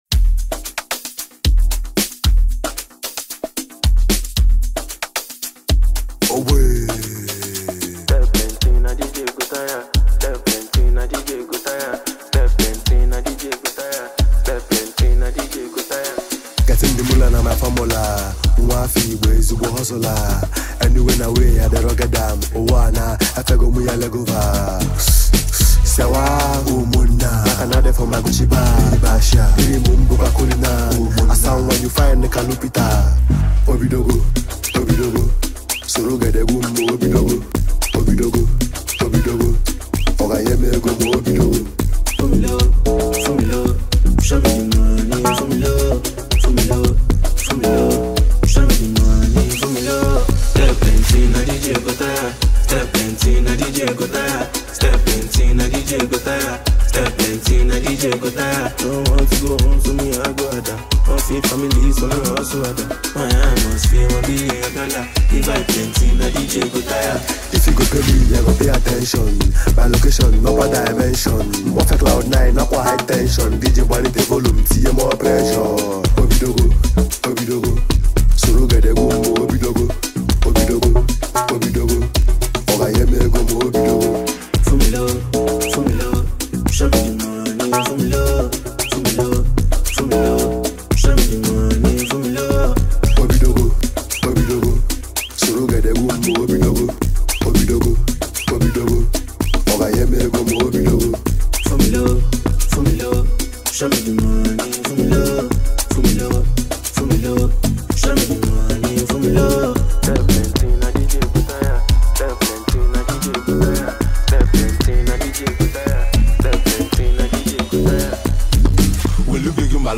smooth and melodic